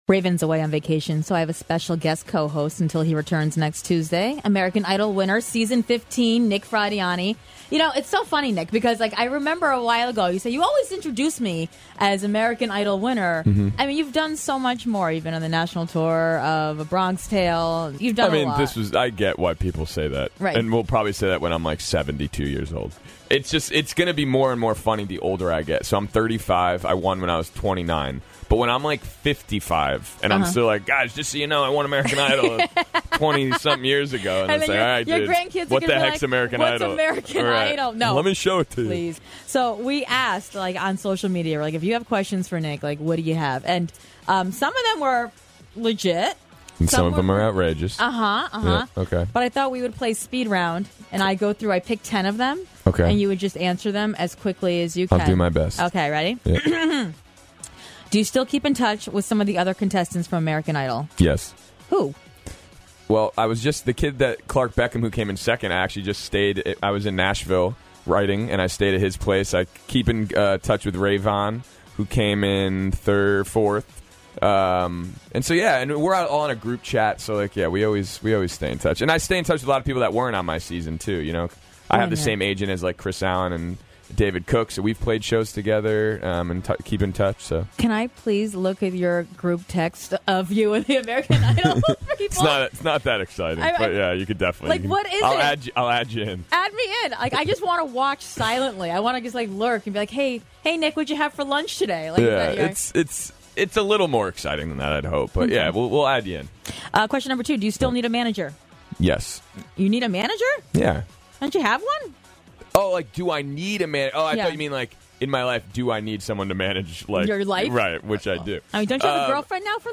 Q & A With Nick Fradiani!